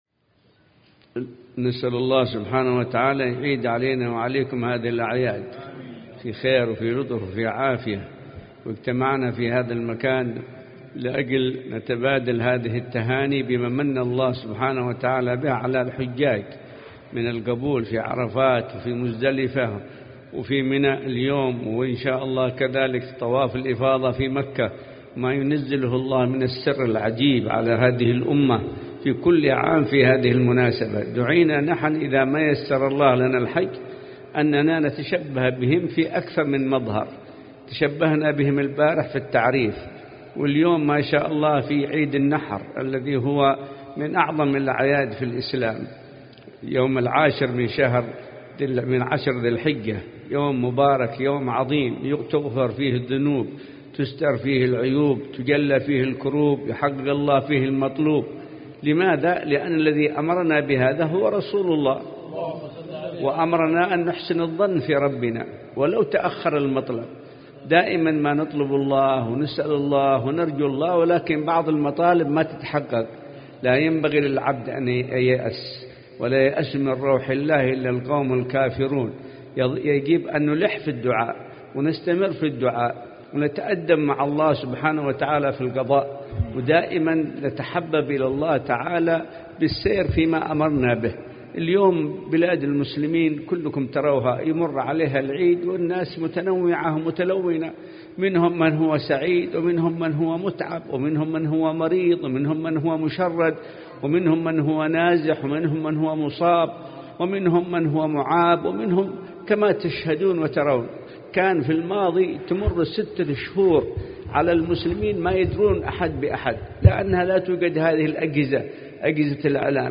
كلمة
بمجلس عواد عيد الأضحى المبارك لعام 1439هـ